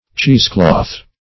cloth`\(ch[=e]z" kl[o^]th`; 115).